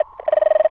cartoon_electronic_computer_code_02.wav